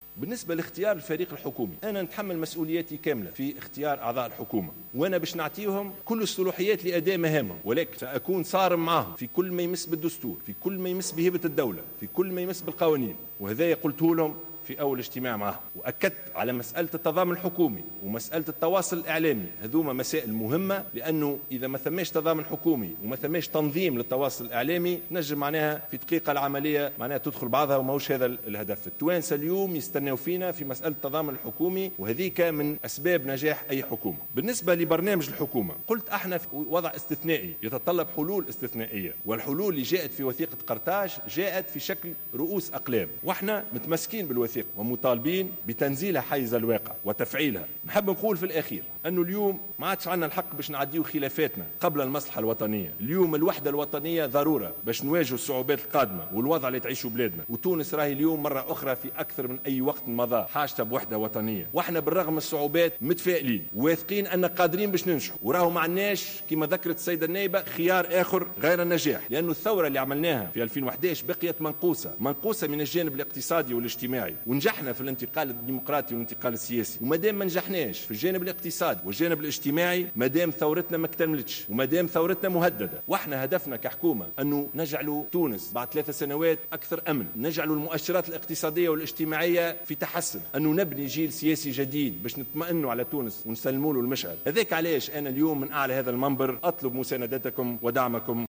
وطالب الشاهد خلال جلسة منح الثقة لحكومته أمس أعضاء حكومته بالحفاظ على مبدأي التضامن الحكومي والتواصل الإعلامي.